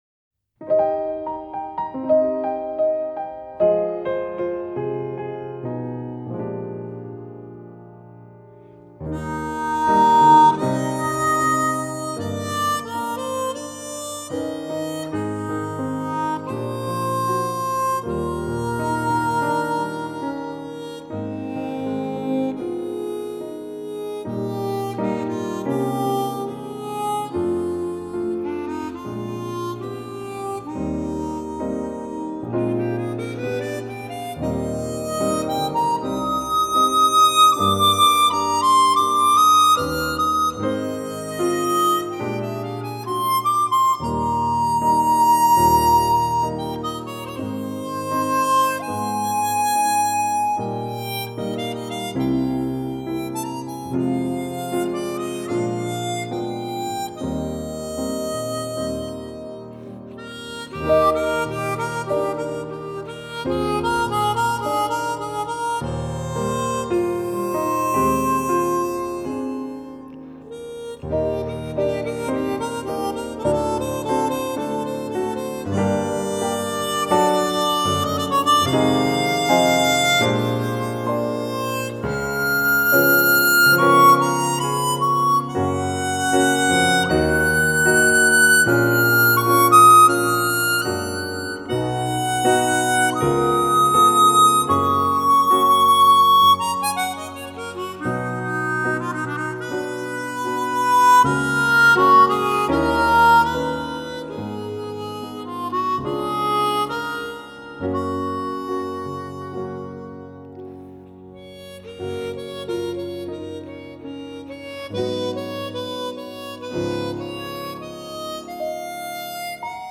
篇，愿温暖流畅的琴声带给大家美好的一天。